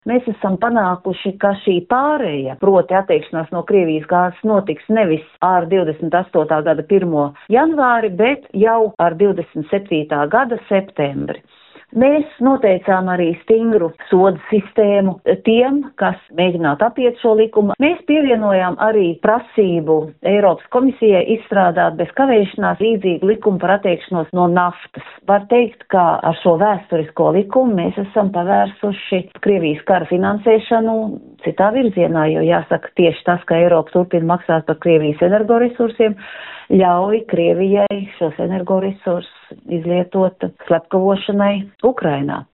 Noslēdzoties garām sarunām, naktī uz 3.decembri, Eiropas Pralaments un Eiropas Savienības dalībvalstis ir panākušas vienošanos par pakāpenisku Krievijas gāzes, sašķidrinātās dabasgāzes, naftas un naftas produktu izņemšanu no apgrozības, to intervijā Skonto mediju grupai pastāstīja Eiropas Parlamenta deputāte Inese Vaidere kuras vadītā grupa panākusi šo vienošanos.